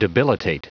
Prononciation du mot : debilitate